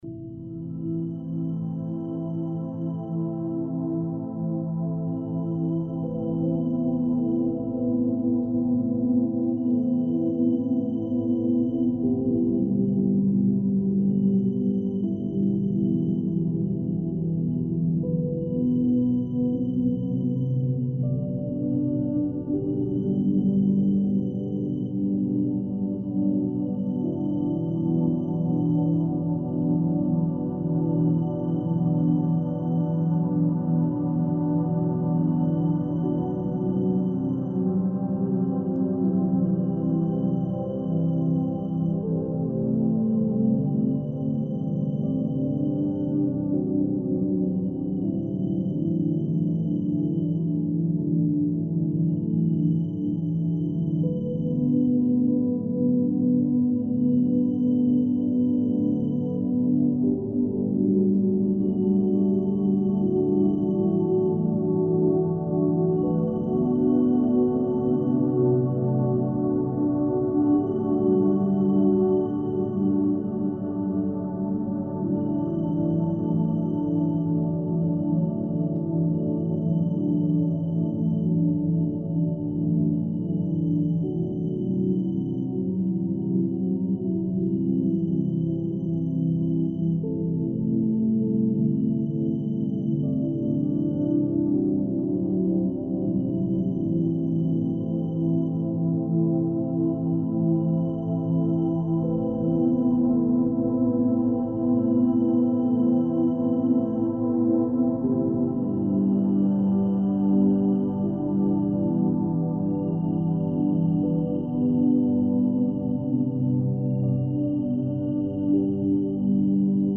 20hz - Beta Binaural beats - Supports Mental Clarity
Mindfulness and sound healing — woven into every frequency.